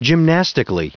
Prononciation du mot gymnastically en anglais (fichier audio)
Prononciation du mot : gymnastically